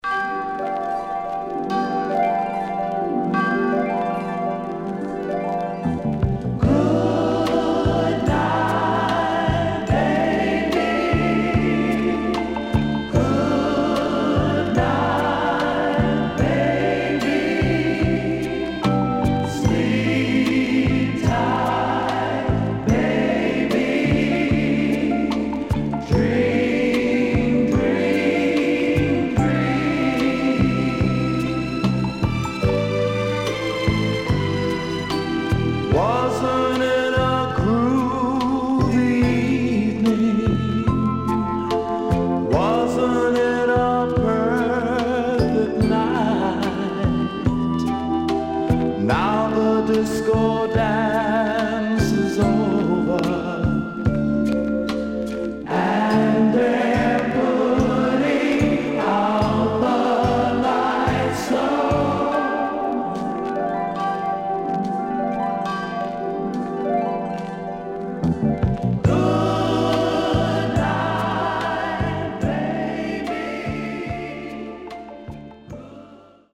CONDITION SIDE A:VG(OK)〜VG+
SIDE A:少しチリノイズ、プチノイズ入ります。